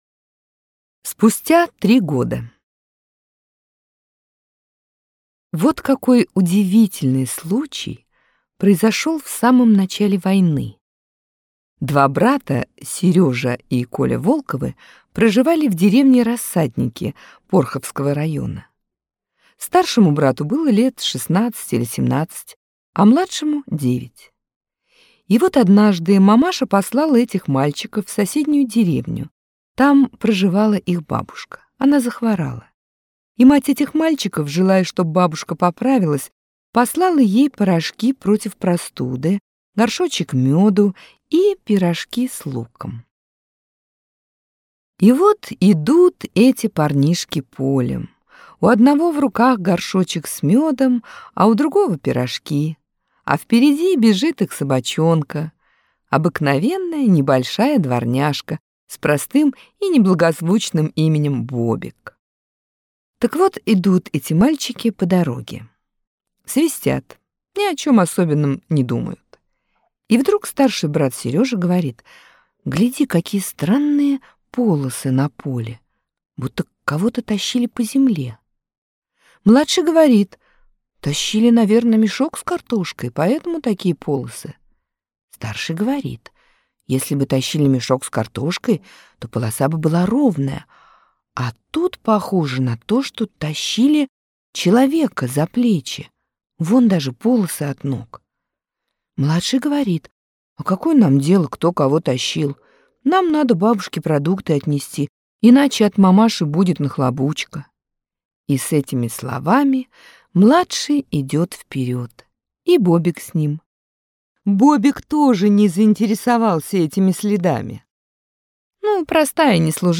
Аудиорассказ «Спустя три года»